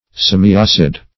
Semiacid \Sem`i*ac"id\, a. Slightly acid; subacid.